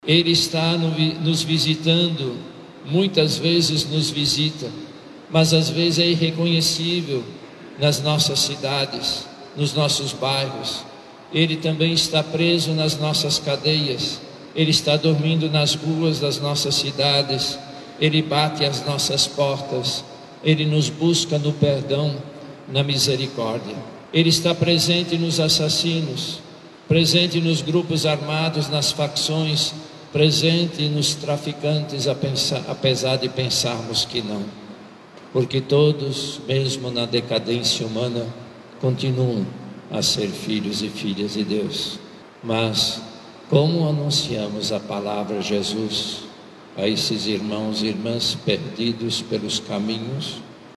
Missa de Natal é realizada na Catedral Nossa Senhora da Conceição
Dom Leonardo nos lembrou, nesta reflexão, que aprendemos também que Jesus veio ao mundo para nos tornar mais humanos e divinos.